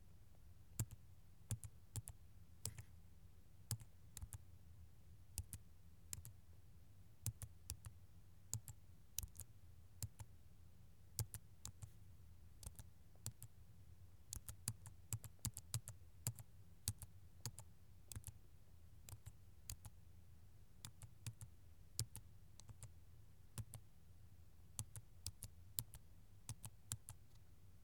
keyboard single strokes
keyboard keystroke typing sound effect free sound royalty free Voices